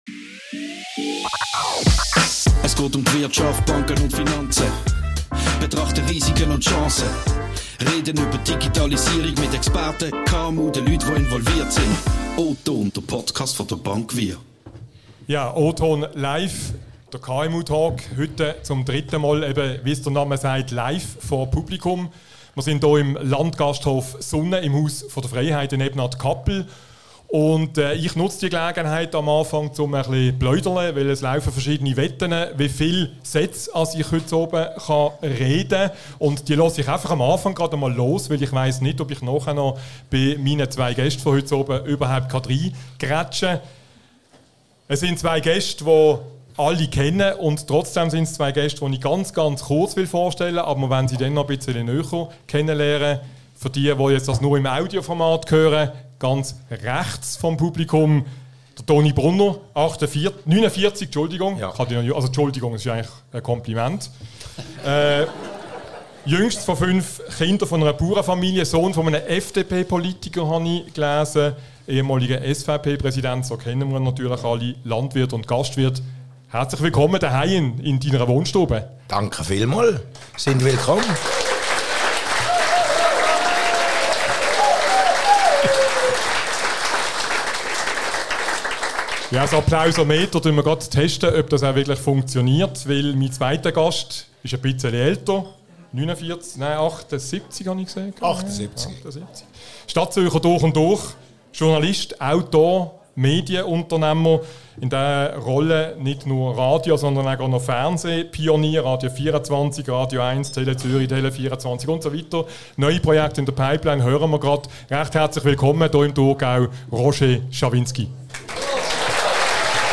Im Live-Podcast in Ebnat-Kappel diskutierten sie über Altersmilde, übten Medienkritik, wetterten über den Schweizer Umgang mit «Too big to fail»...